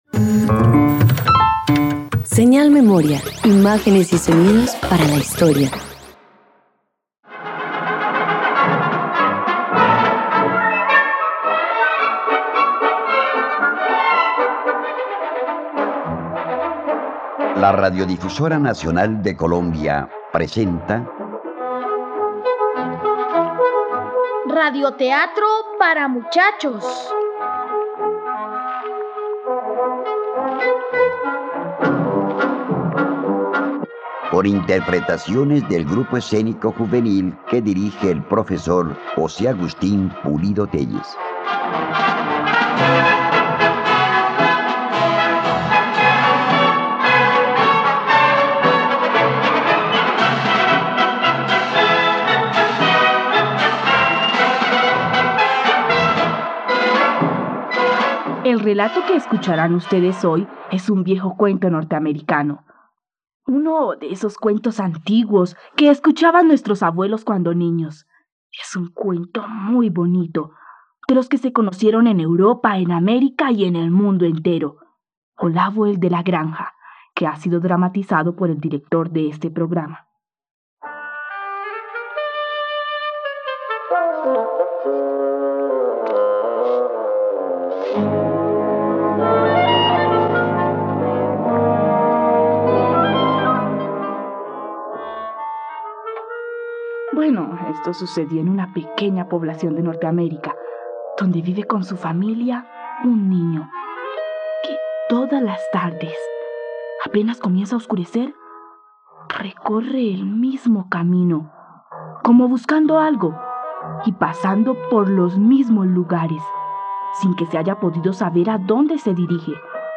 Olavo el de la granja - Radioteatro dominical | RTVCPlay